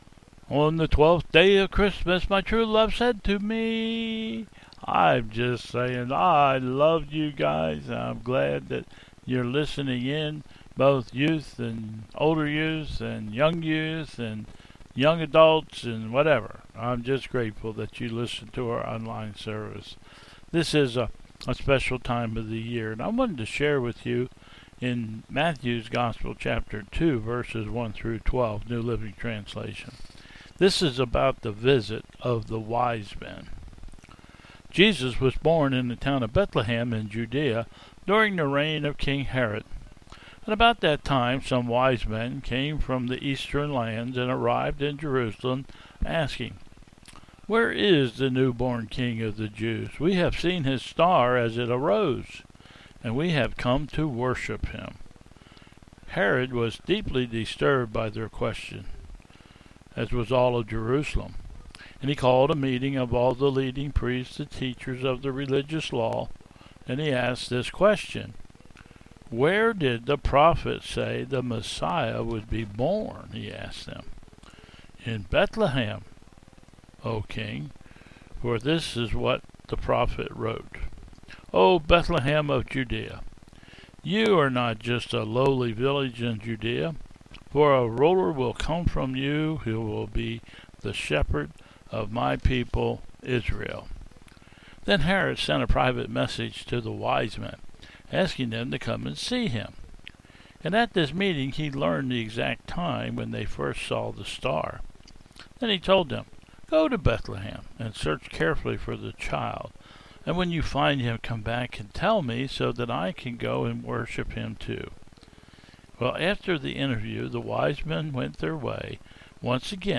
Bethel 01/03/21 Service
(08:54) - "Youth" Message : "Where is He?" . (08:54) Though addressed to the "Youth", whom we truly miss seeing on Sundays, this message is for all of us.